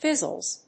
/ˈfɪzʌlz(米国英語)/